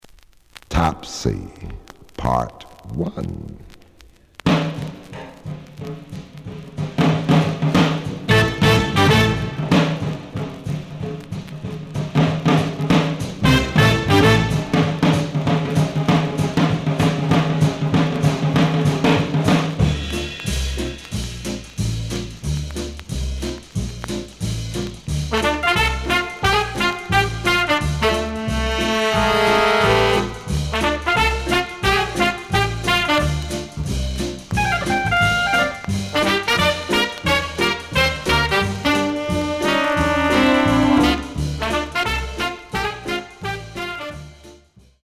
The audio sample is recorded from the actual item.
●Genre: Big Band